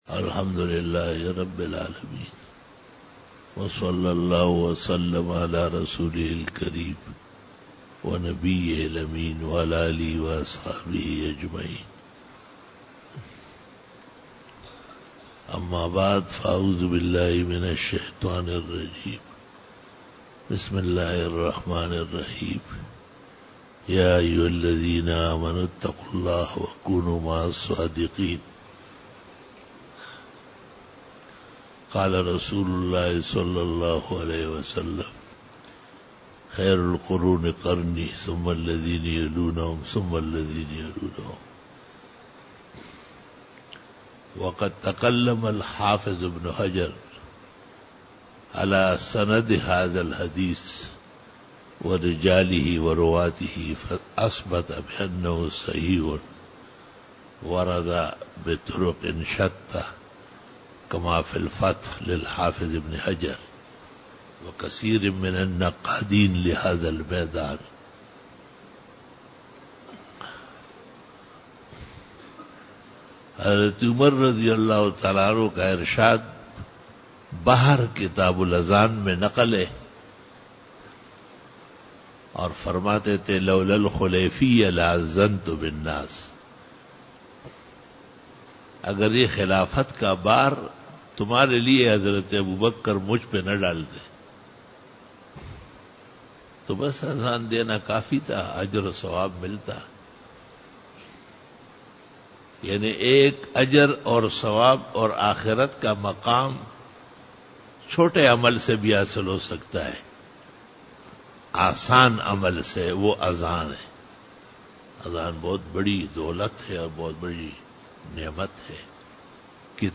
49 BAYAN E JUMA TUL MUBARAK (07 December 2018) (28 Rabi ul Awwal 1440H)